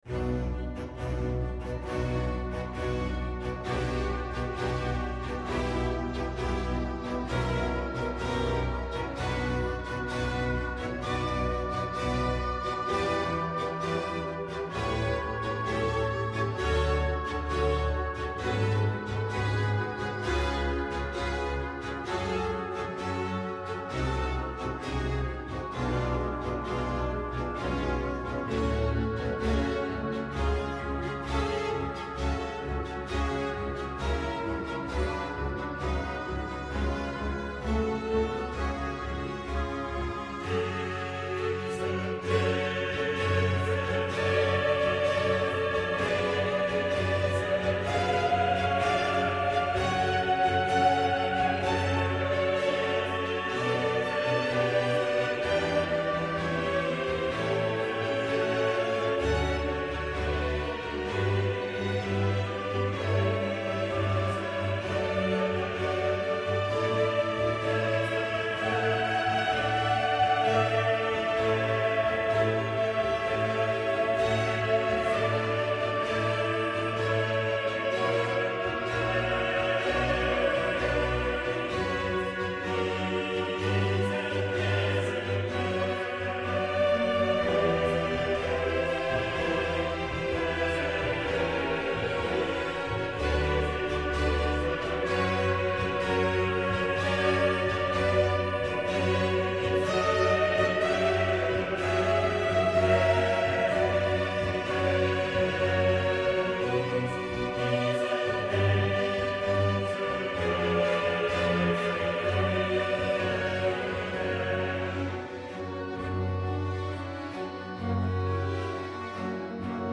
Incontro con Thomas Hengelbrock
Lo abbiamo incontrato a Parigi, dove vive.